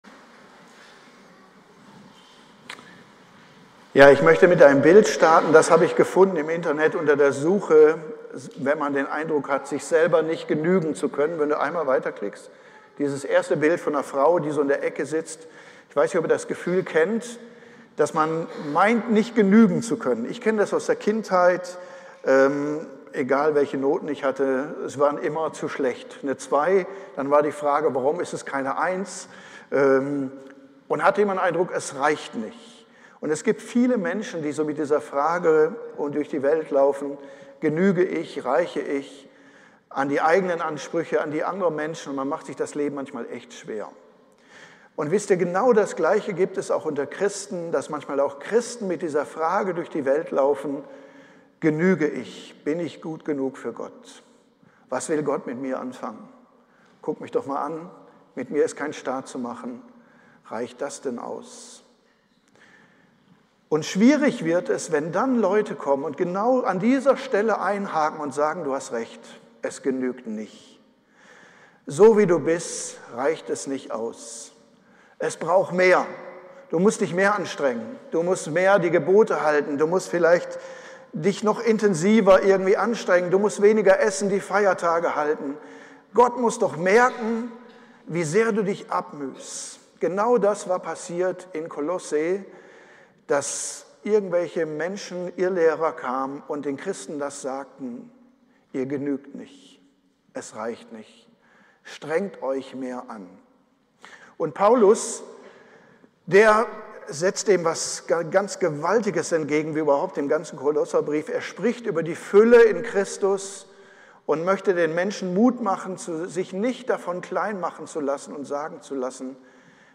Jüngerschaft Kolosser Audio Player anzeigen Audiodatei speichern Predigtreihe